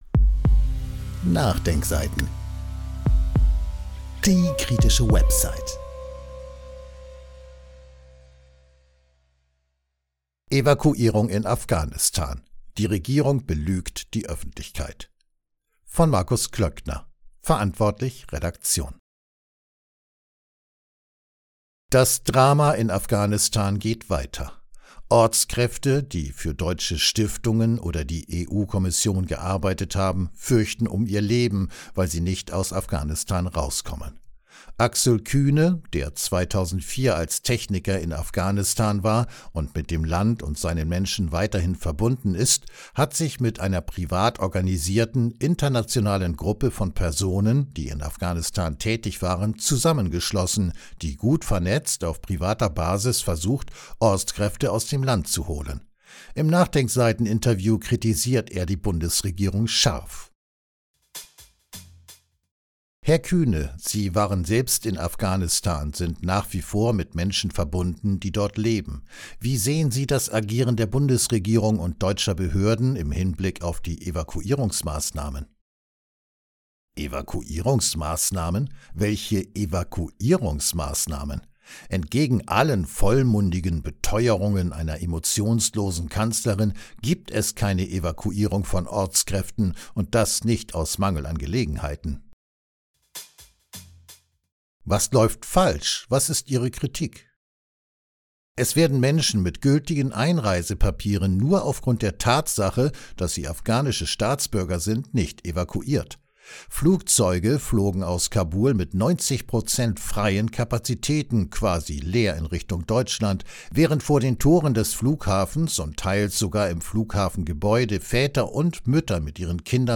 Im NachDenkSeiten-Interview kritisiert er die Bundesregierung scharf.